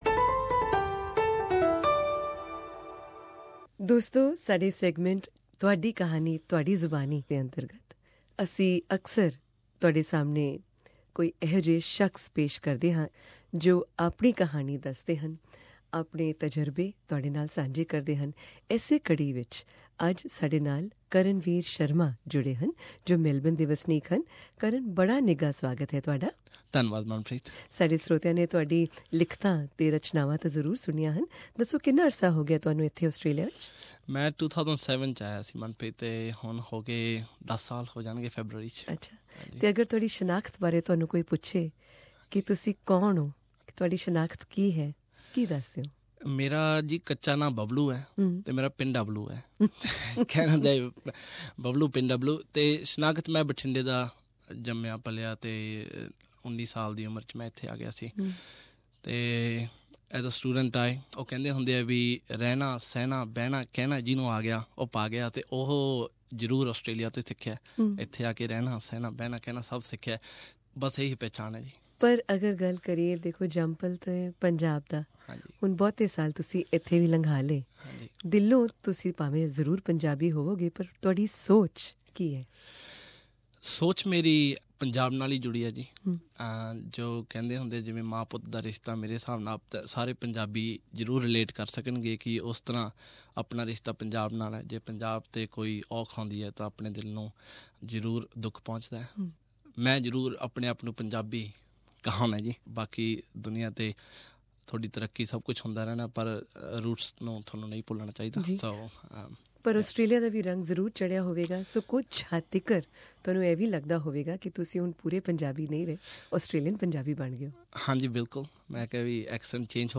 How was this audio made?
SBS studios in Melbourne